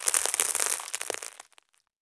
debris02.wav